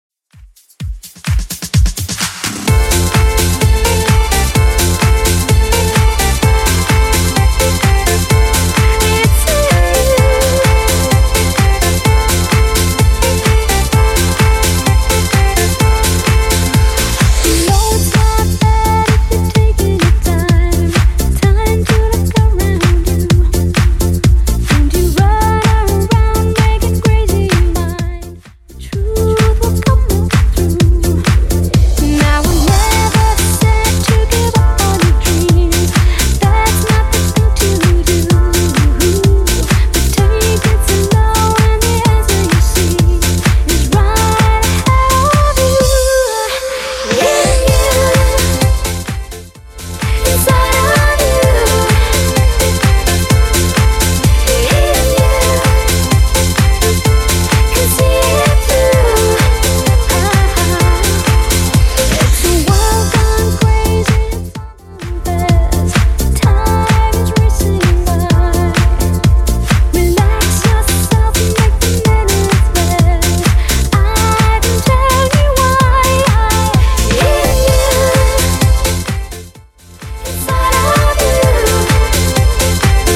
Genre: TOP40
BPM: 115